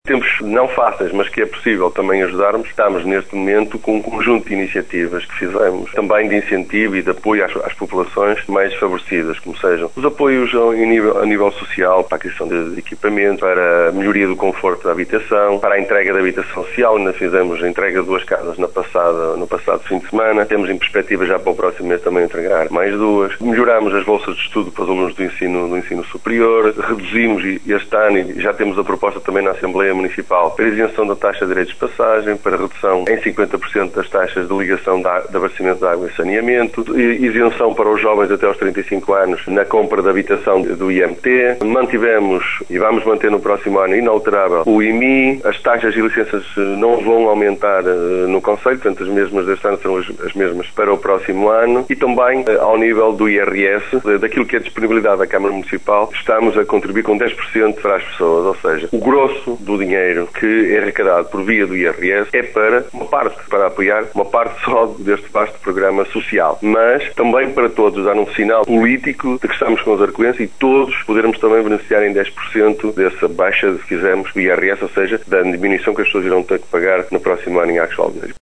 Os mais carenciados não são esquecidos, como sublinhou à Rádio Caminha o presidente da Câmara, João Manuel Esteves.